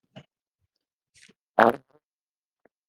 nfe-fart.ogg